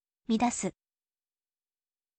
midasu